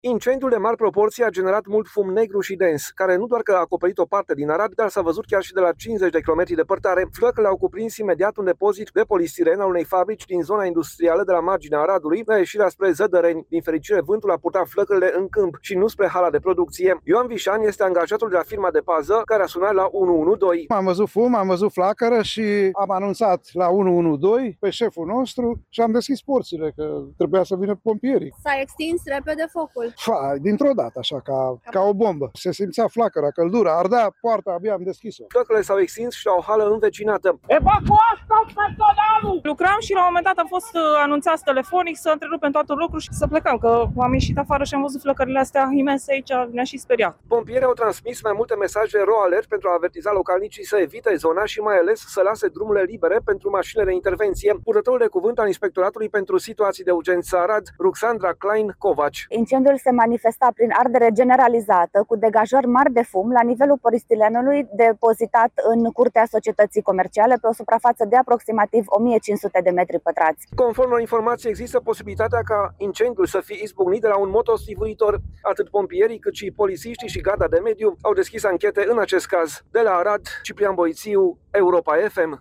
Reporter: S-a extins repede focul?
„Lucram și, la un moment dat, am fost anunțați telefonic să întrerupem tot lucrul și să plecăm. Când am ieșit afară și am văzut flăcările astea imense aici, m-am și speriat”, spune o femeie.